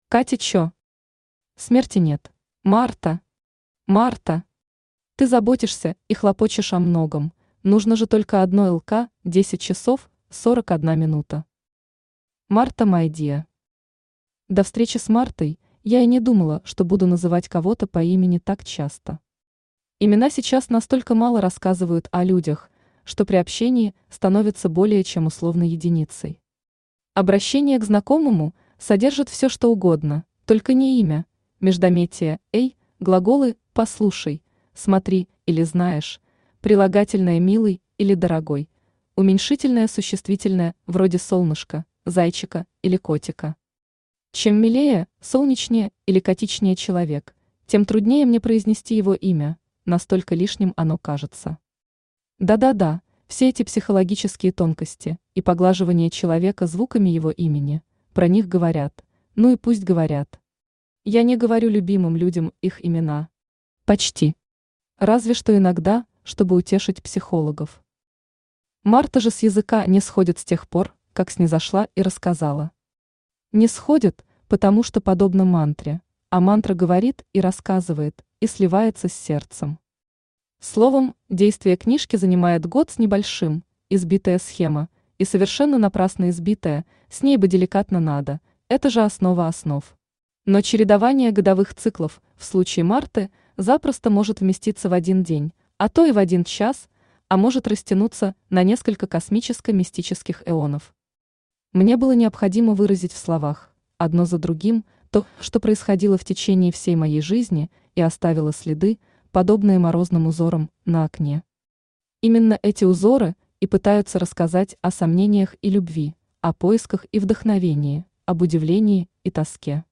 Аудиокнига СМЕРТИ НЕТ | Библиотека аудиокниг
Aудиокнига СМЕРТИ НЕТ Автор Катя Че Читает аудиокнигу Авточтец ЛитРес.